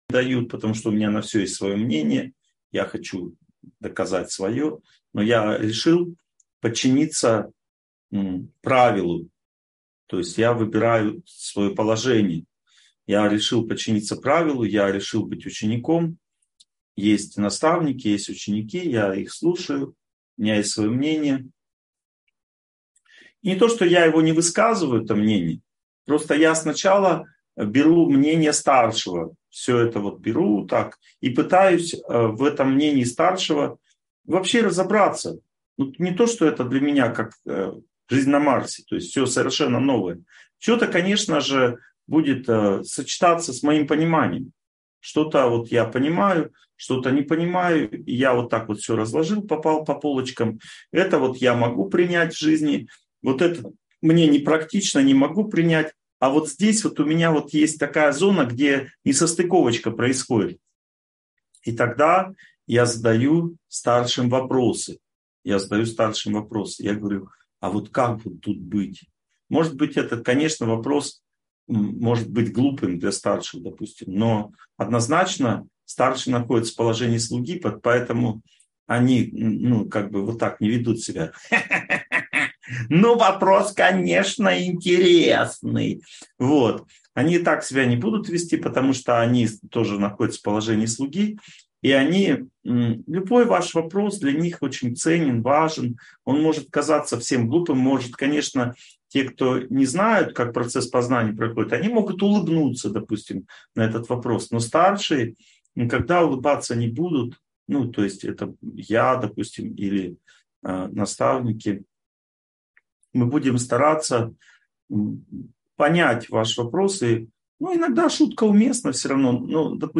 Здоровые отношения (онлайн-лекция, 2023)